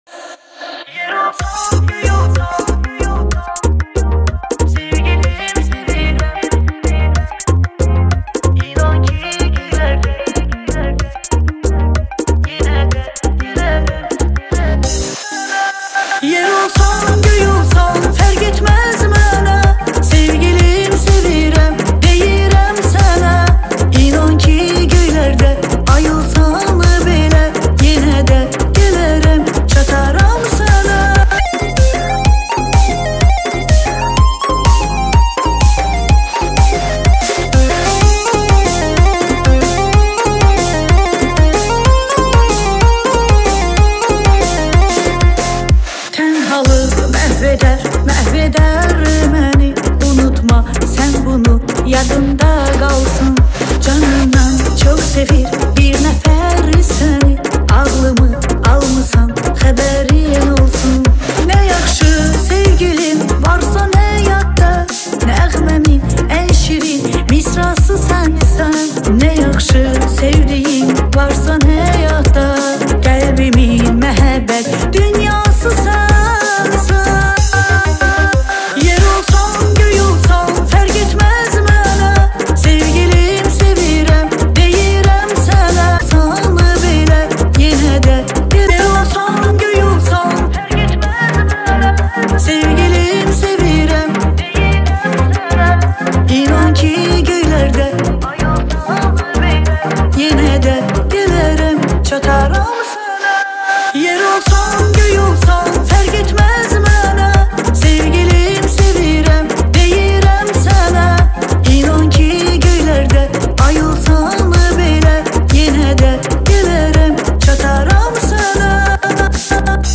دانلود آهنگ ترکی